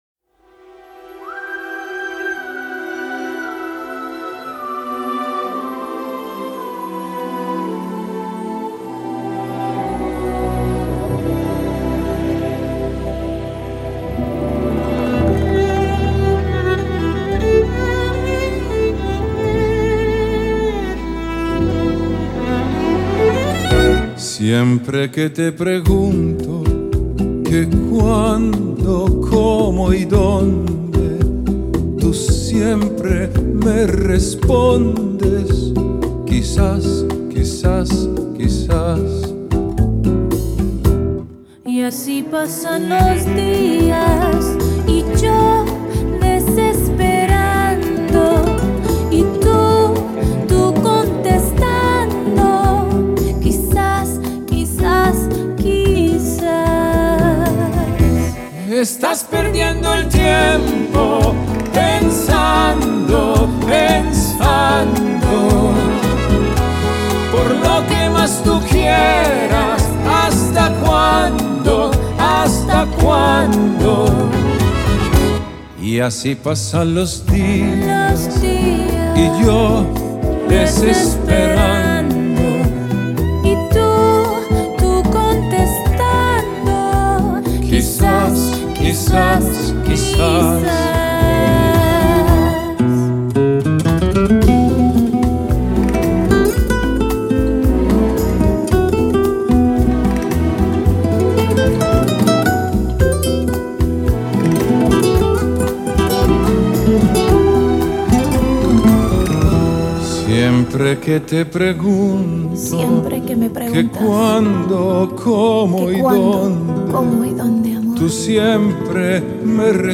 Genre: Vocal, Classical, Crossover